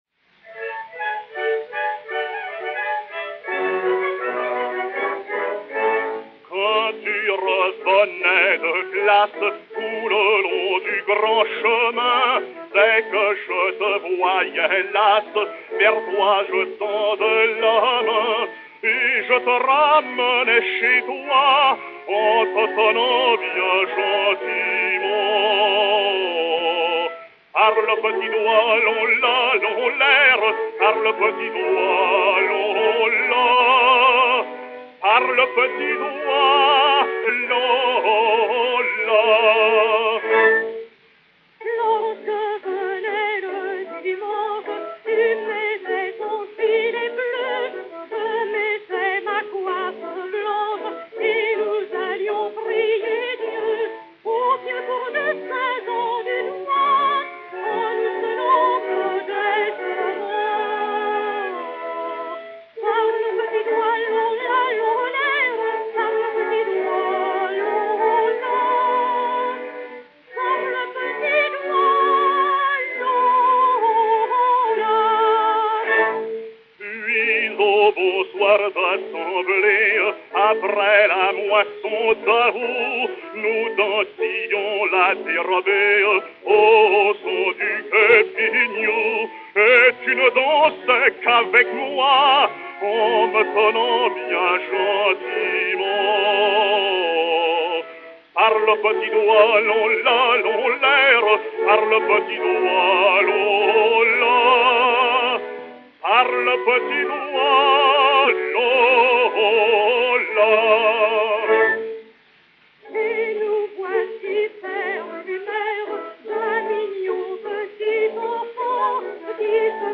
chanson (par. et mus.
Orchestre
Disque Pour Gramophone 34224, mat. 15410u, enr. à Paris le 04 novembre 1909